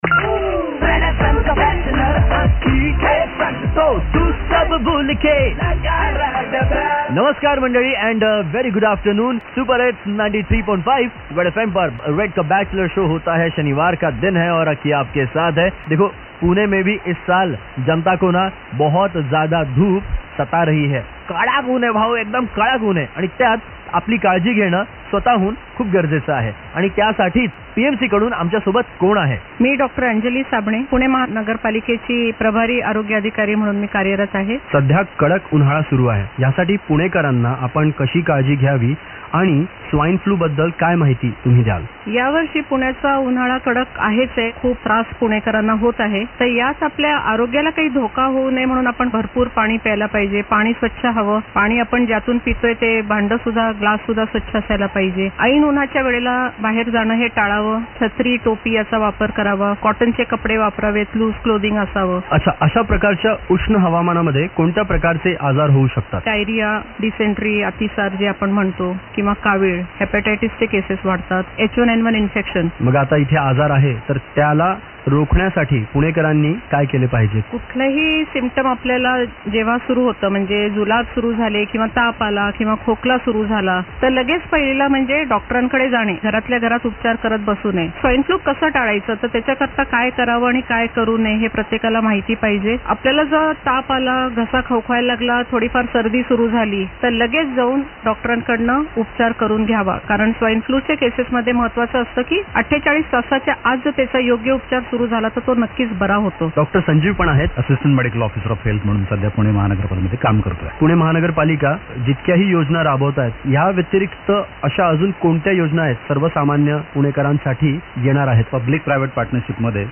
Summer Health Campaign Interview of PMC